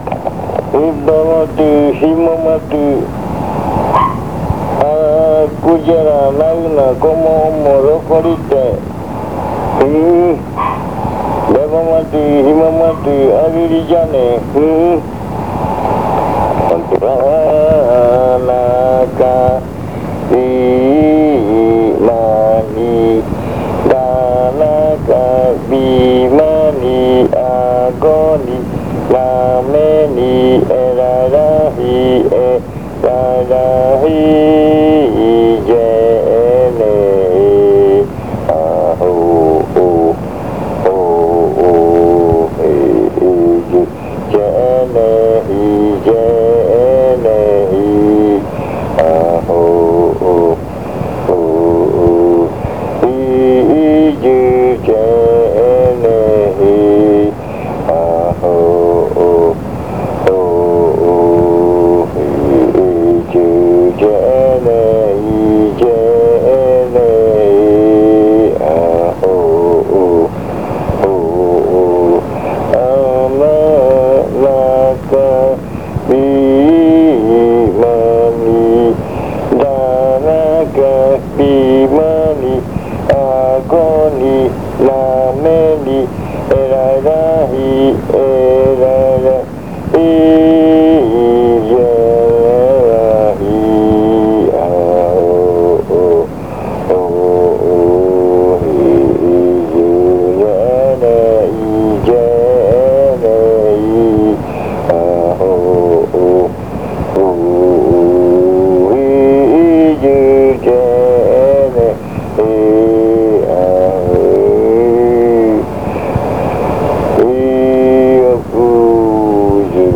Leticia, Amazonas
Arrimada (zɨjɨna rua), de 2:35-4:00 PM.
Entry chant (zɨjɨna rua), from 2:35-4:00 PM.